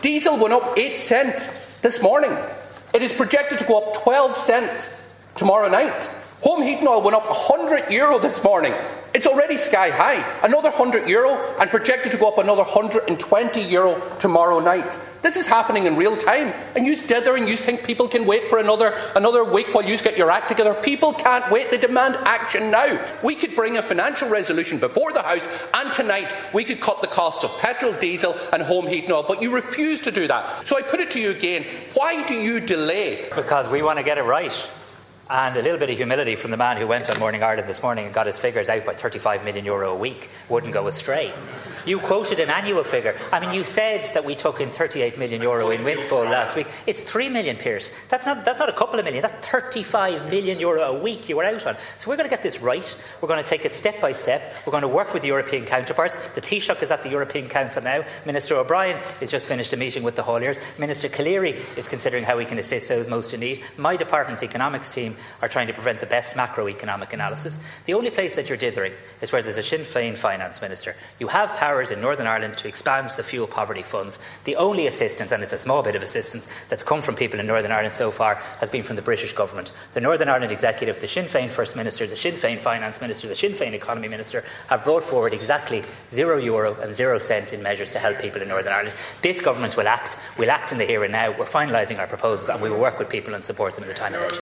Tanaiste Simon Harris has told the Dail that the government will bring forward measures to alleviate the impact of rising fuel prices on Tuesday of next week.
Responding to Donegal Deputy and Sinn Fein Finance Spokesperson Pearse Doherty, Mr Harris said the Transport Minister has had a series of meetings today, and cabinet will finalise a suite of targeted measures at its meeting on Tuesday morning before bringing it to the Dail that day.